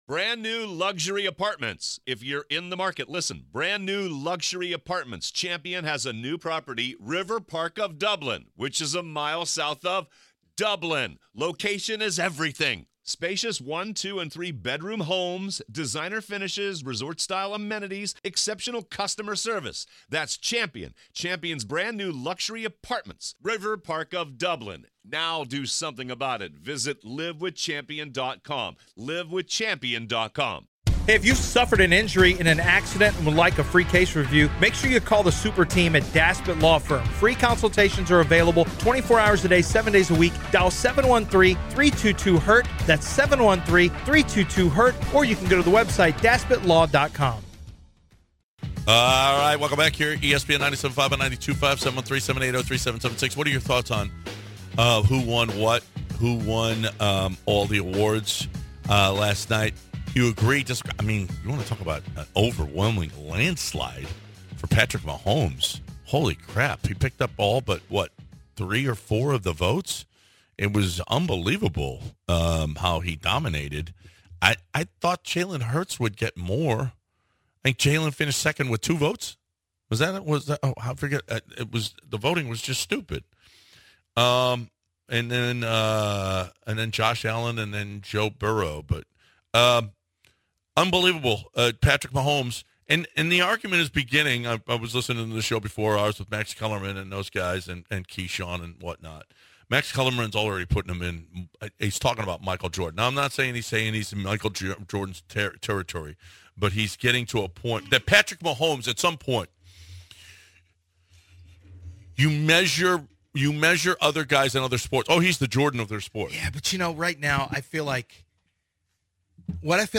Who is the greatest Rockets free agent of all-time? The Bench would also be joined by ESPN National Correspondent Sal Paolantonio, who would speak on the Super Bowl from an Eagles perspective. Listen in to hour two of The Bench.